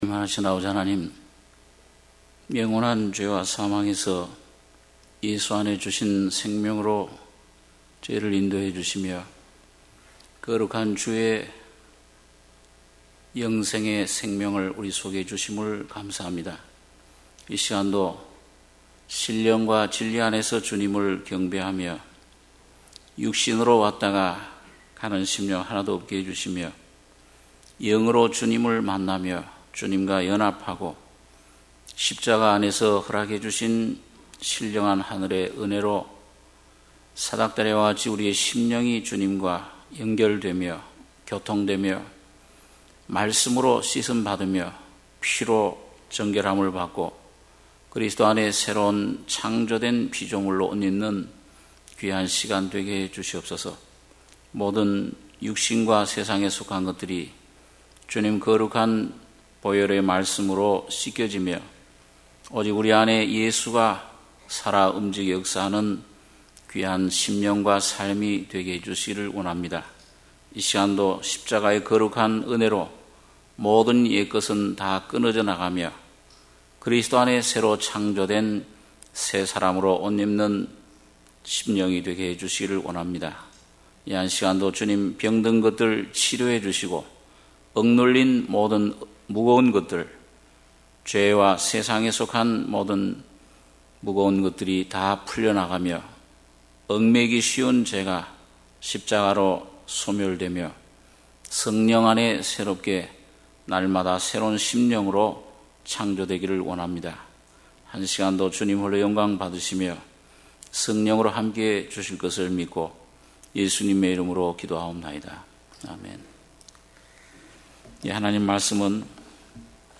수요예배 - 고린도전서 12장 1~12절